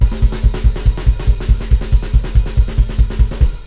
Black Metal Drum Rudiments
Beat 1 - The Thrash Beat
Patterns 1 and 2 sound basically the same, and so 1 is used when the music is sufficiently slow to not wear your hand out, and 2 is used when the music is fast.
thrash2.wav